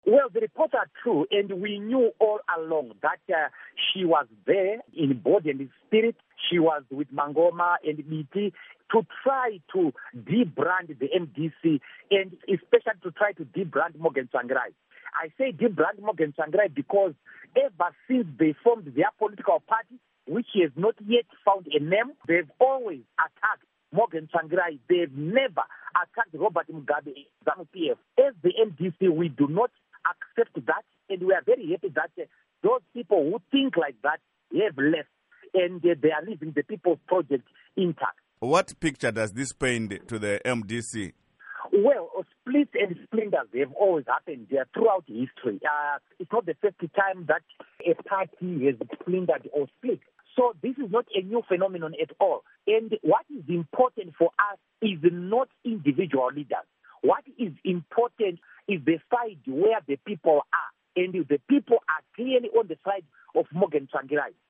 Embed share Interview With Douglas Mwonzora by VOA Embed share The code has been copied to your clipboard.